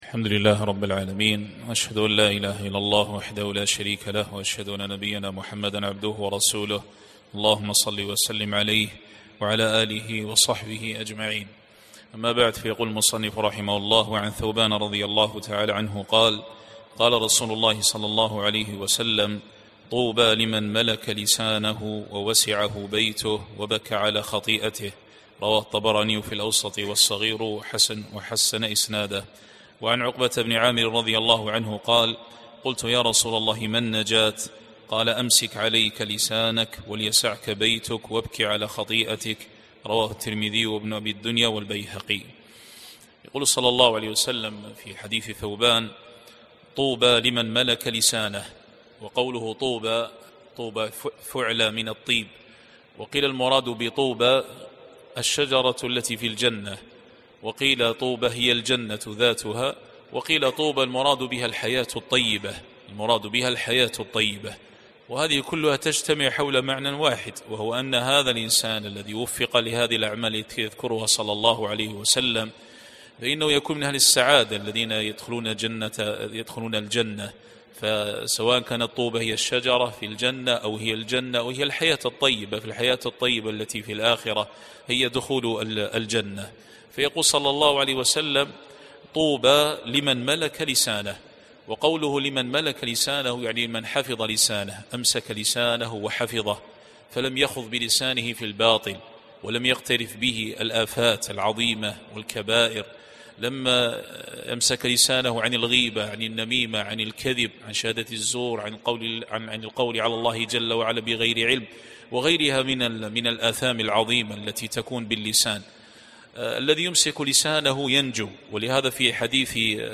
الدرس السابع والأربعون -47- 📑 شرح كتاب الأدب وغيره من صحيح الترغيب والترهيب باب : الترغيب في العزلة لمن لا يأمن على نفسه عند الاختلاط. شرح حديث: طوبى لِمَنْ مَلكَ لِسانَهُ، ووَسِعَهُ بيتُه، وبكى على خَطيئَتِه .